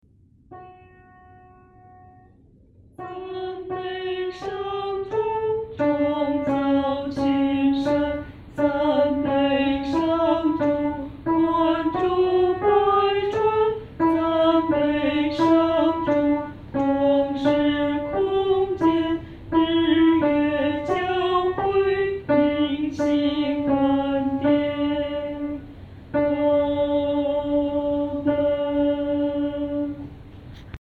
女低
这首诗的曲调活跃，和声变化色彩丰富；与前面五首赞美诗的和声处理方面很不同，是一首典型的“众赞歌”。